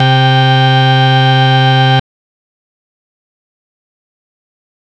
Organ (4).wav